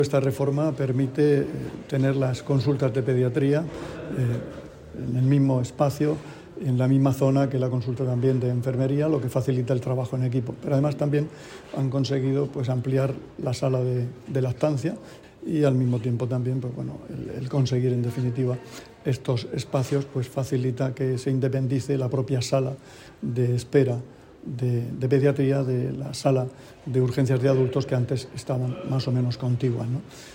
Sonido/ Declaraciones del consejero de Salud sobre la ampliación de la zona materno- infantil del centro de Salud de Calasparra.